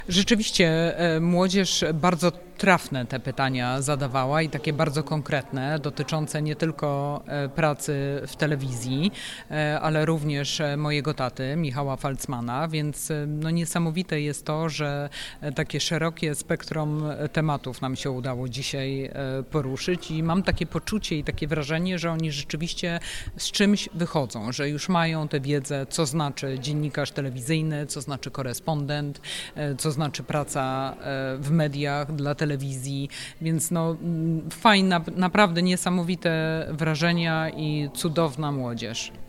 We wtorkowy poranek (20 maja) odbyło się kolejne spotkanie z cyklu „Śniadanie Mistrzów PWT”.
Młodzież zgromadzona w auli Papieskiego Wydziału Teologicznego była przygotowana i pytała gościa o różne kwestie, nawet te, o których wielu już dziś nie pamięta.